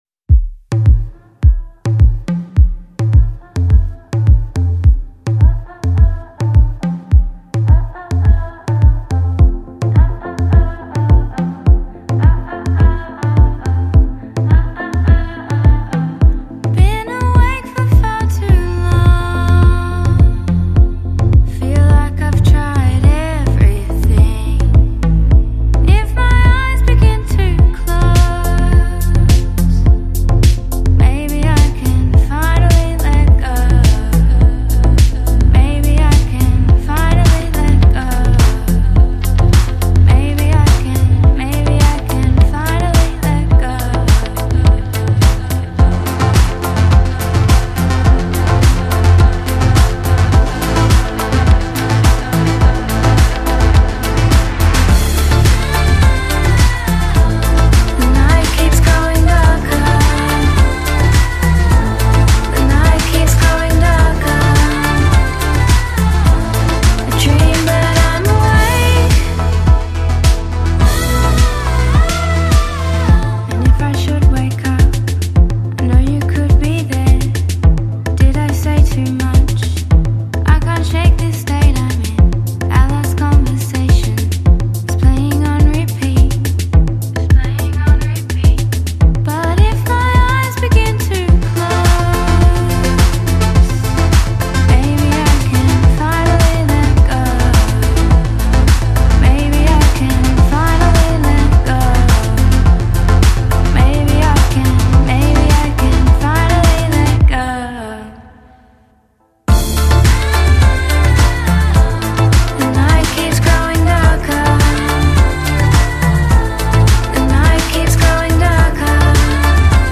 Melbourne electronic project
soulful electronic track